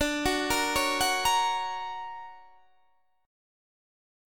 D+M7 Chord
Listen to D+M7 strummed